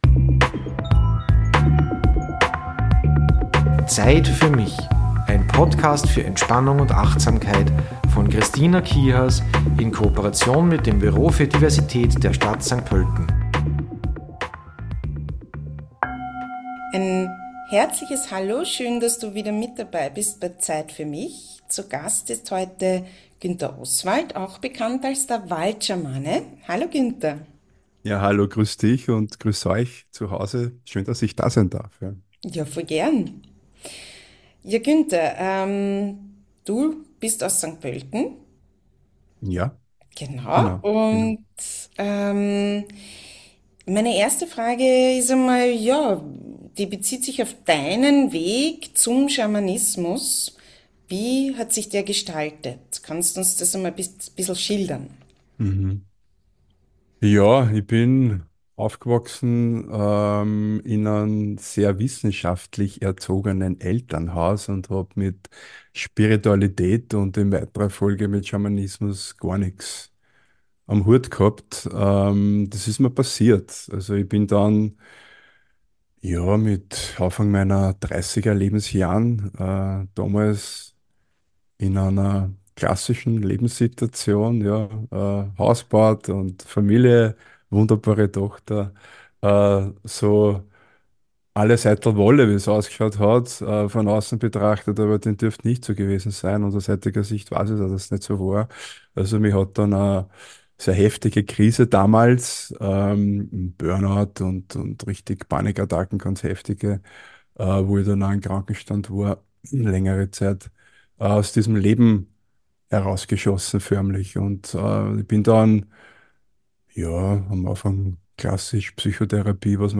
Beschreibung vor 3 Monaten Wir freuen uns darüber, einen Blick für Diversität zu weiten und nährend zu wirken, dazu findest du in jeder Folge auch einen Entspannungsteil mit Meditationen, Atemübungen etc. - das, was gerade zur aktuellen Folge gut passt. Schamanismus kann einen Weg darstellen, deinem Bedürfnis nach tiefer Verbindung, Heilsein und Bewusstseins-Erweiterung Raum zu geben.